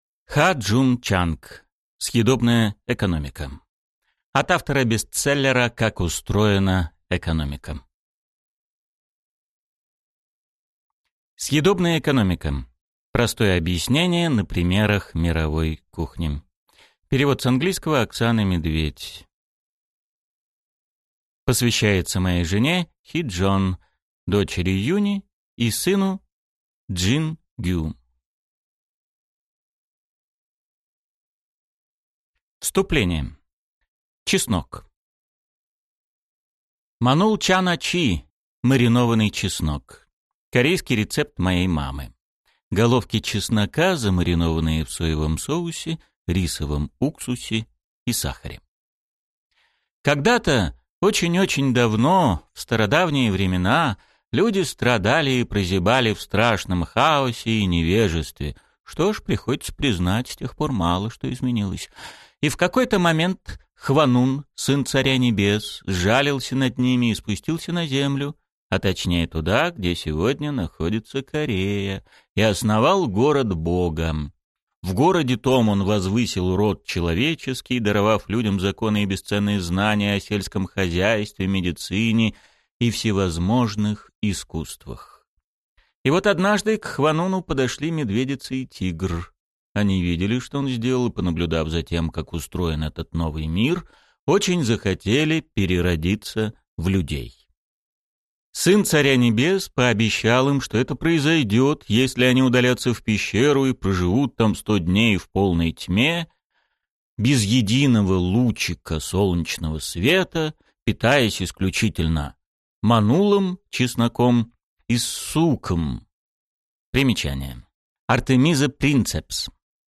Аудиокнига Съедобная экономика. Простое объяснение на примерах мировой кухни | Библиотека аудиокниг